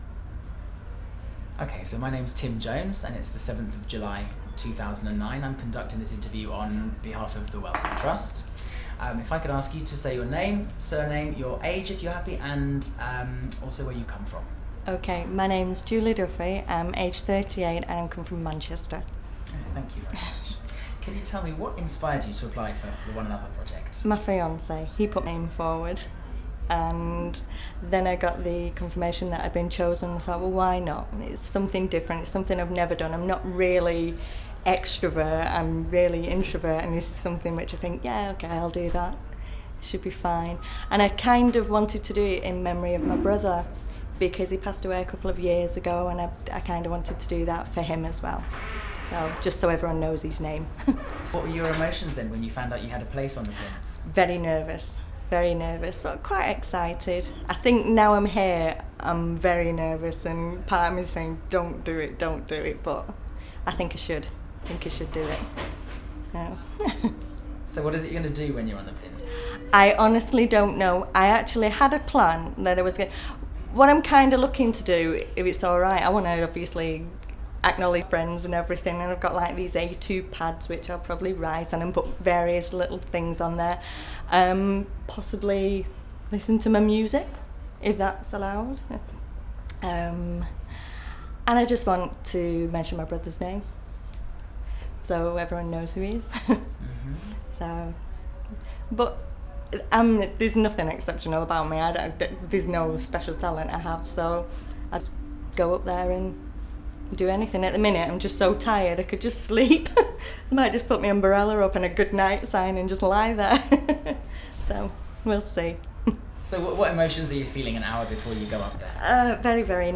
Audio file duration: 00:07:12 Format of original recording: wav 44.1 khz 16 bit MicportPro.